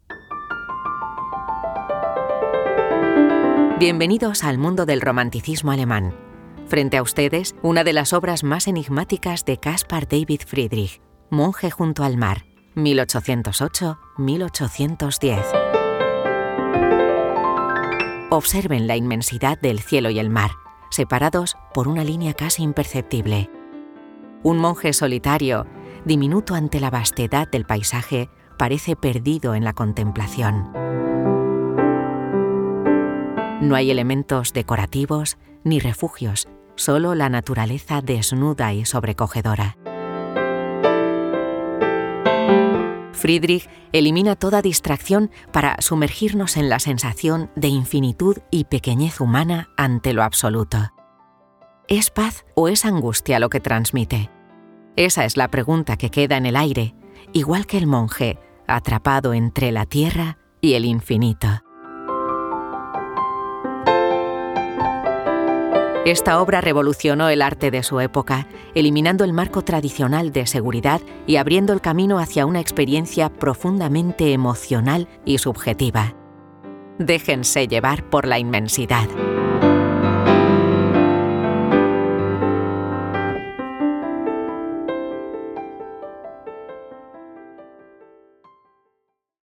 Spaanse voice-over
Natuurlijk, Veelzijdig, Diep, Toegankelijk, Warm
Audiogids
Ik bied een hoge geluidskwaliteit met snelle levering en kan mijn stem aanpassen aan uw project.